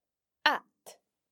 We will cover both the short a and long à forms of the vowel a, with examples to get you used to the sound.